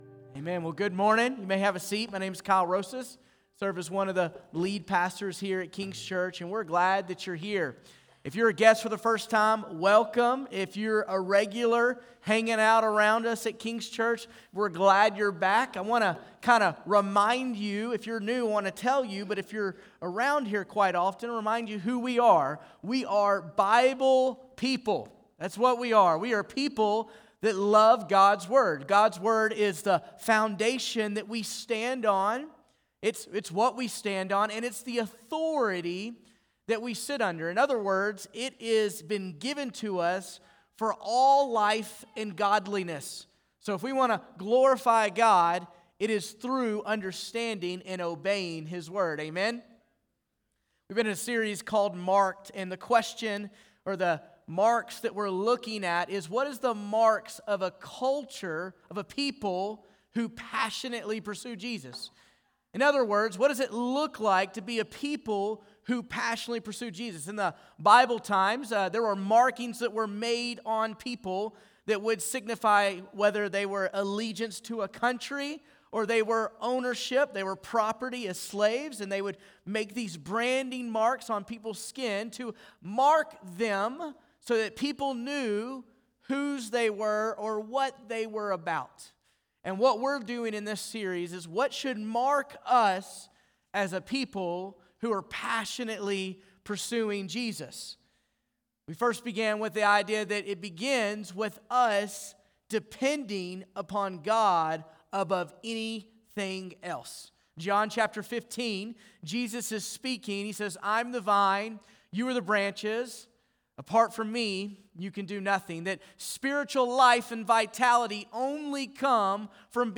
Jan25Sermon.mp3